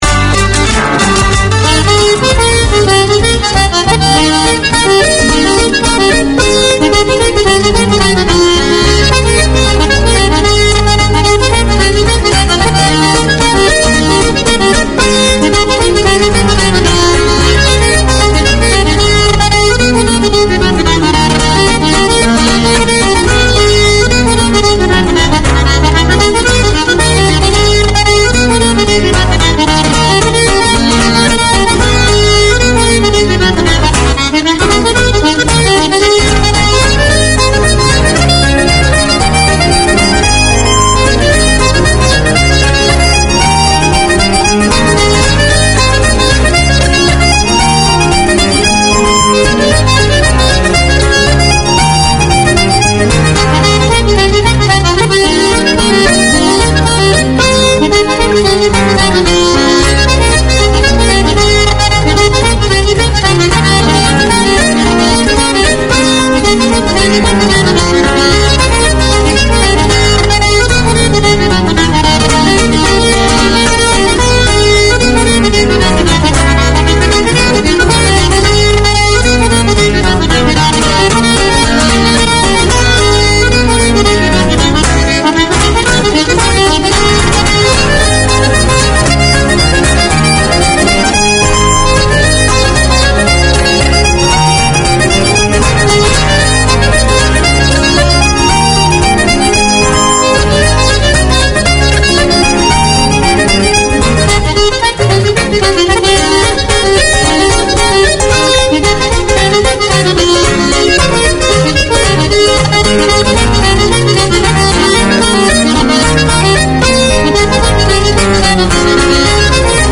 Radio made by over 100 Aucklanders addressing the diverse cultures and interests in 35 languages.
Featuring a wide range of Irish music and the occasional guest, including visiting Irish performers, politicians, sports and business people.